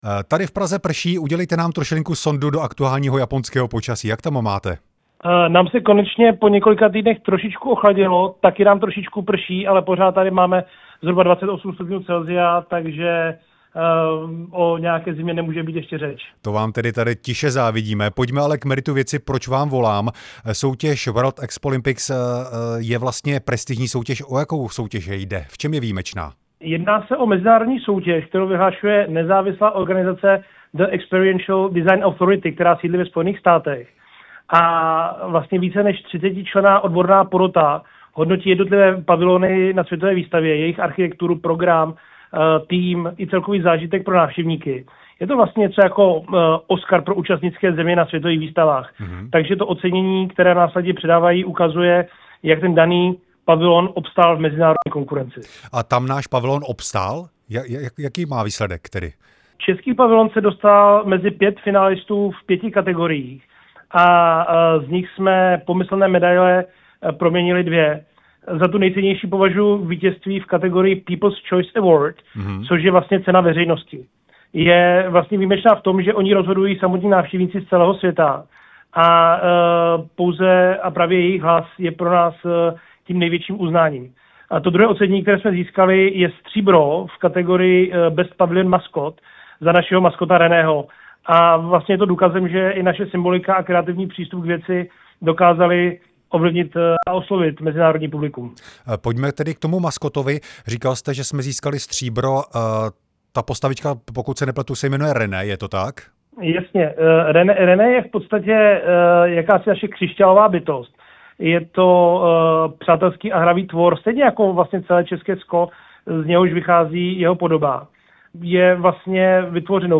Rozhovor s generálním komisařem české účasti na Expo 2025 Ondřejem Soškou